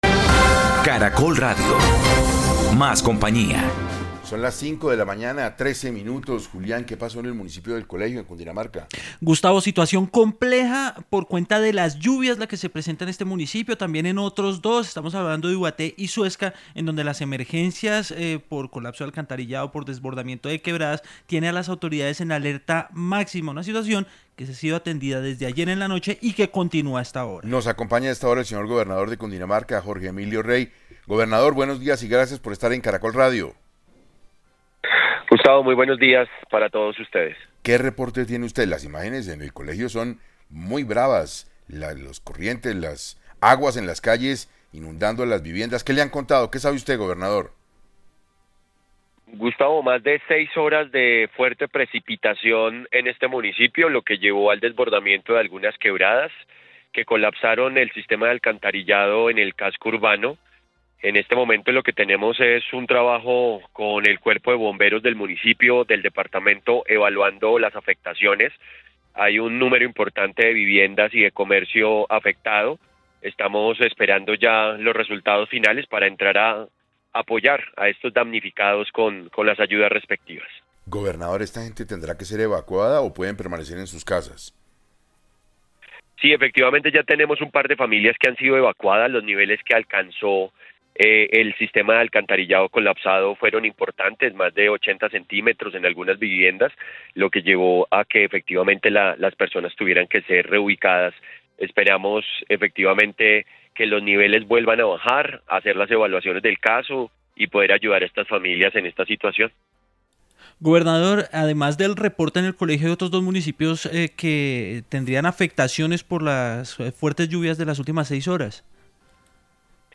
El gobernador Jorge Emilio Rey entregó en 6AM el reporte de las fuertes inundaciones que afectaron al municipio cundinamarqués, durante la madrugada de este jueves.
En 6AM de Caracol Radio estuvo el gobernador de Cundinamarca, Jorge Emilio Rey, para hablar sobre los daños que se han presentado, la cantidad de damnificados por esta emergencia y qué otros municipios del departamento han resultado afectados.